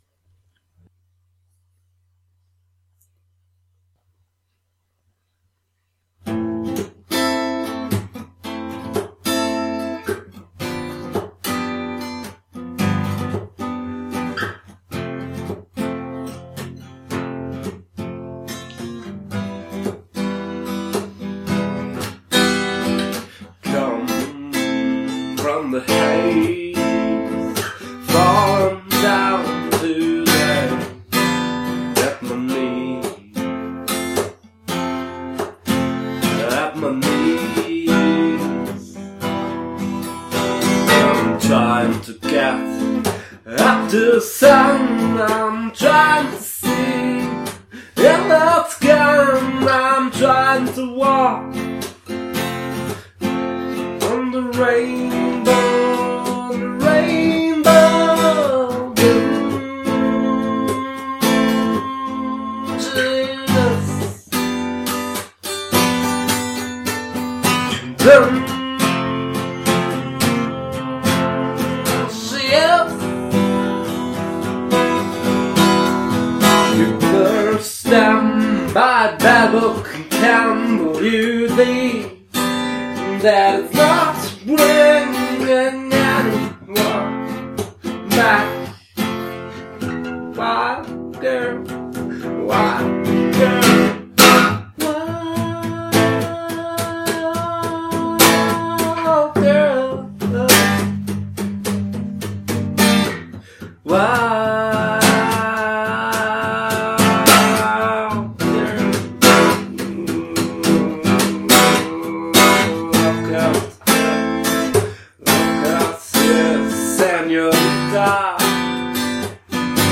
Musikrichtung/Genere/GenreAlternativ